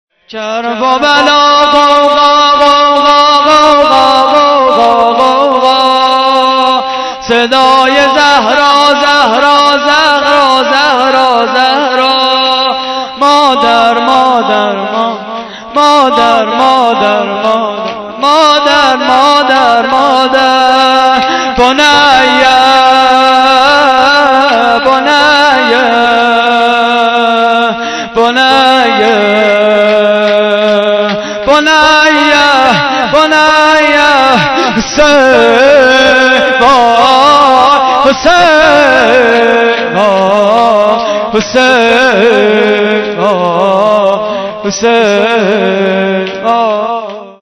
مراسم عزاداری ماه محرم
صوت مراسم:
شور: کرب و بلا غوغا؛ پخش آنلاین |